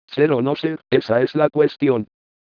El programa soporta diferentes idiomas y tipos de voces, permite que configuremos el interfaz de usuario cambindole el aspecto, entre otras funciones.
Con CoolSpeaking podremos convertir a archivo de sonido con formato WAV cualquier texto como mensajes de correo, cartas, páginas web, etc. El programa soporta diferentes idiomas y tipos de voces, permite que configuremos el interfaz de usuario cambindole el aspecto, entre otras funciones.